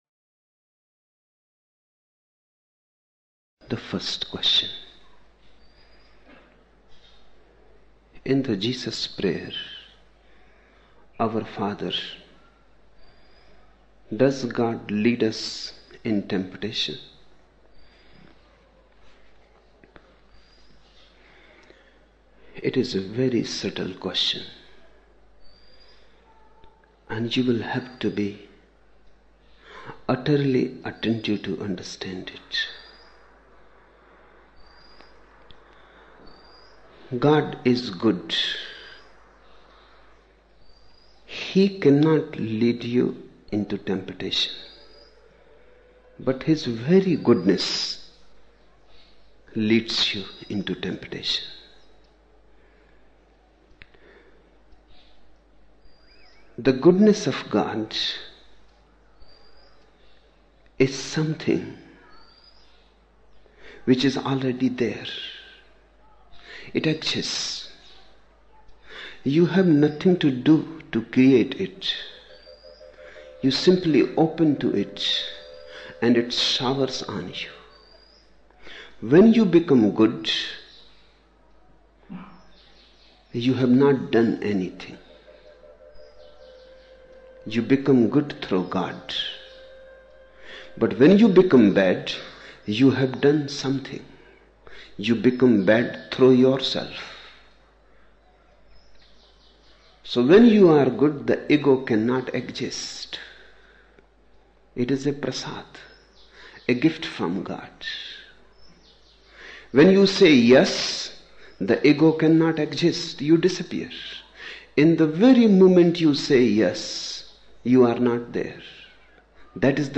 22 October 1975 morning in Buddha Hall, Poona, India